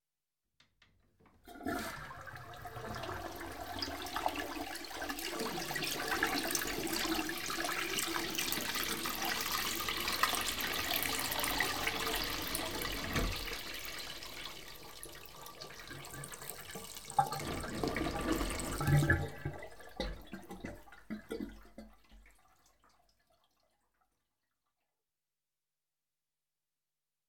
Ya know, your standard AI slop.